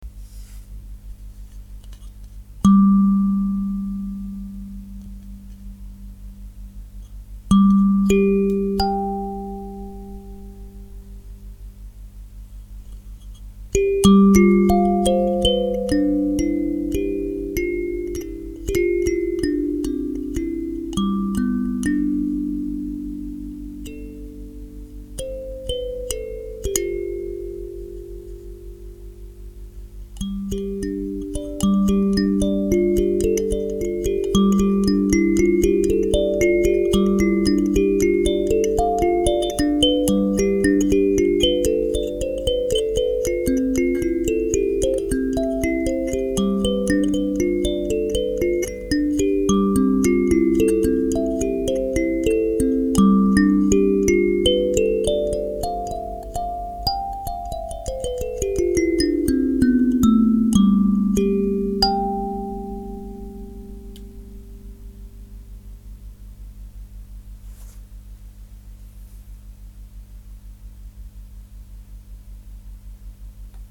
ボードタイプカリンバ
今回は振動の楽しみが味わえる様な楽器にしようと思い板状のカリンバを作ってみました。
そして駒付近が一番厚くなる様に本体に独特の勾配を持たせた事で、シンプルながらもキーの響きをしっかりと本体に伝える構造となりました。
音程はg3~g5のダイアトニック。
0402boardtype.mp3